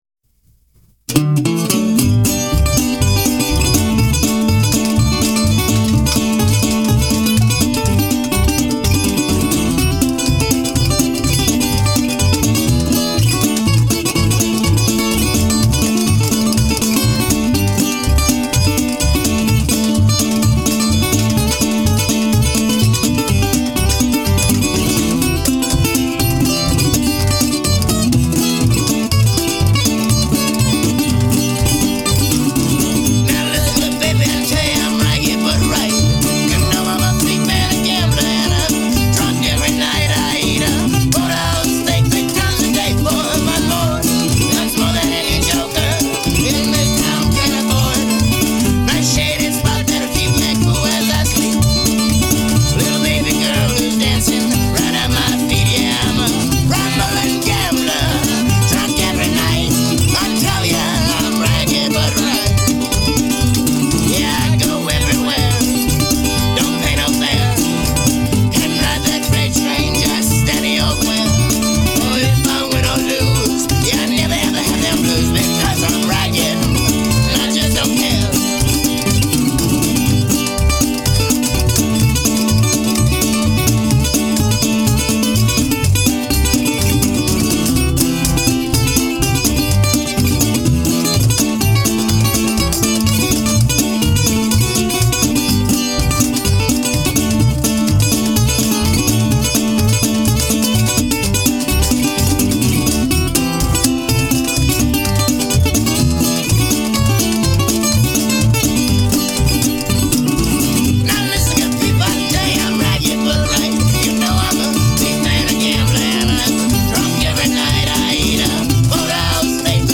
A ragtime gallimaufry from Eugene, Oregon U$A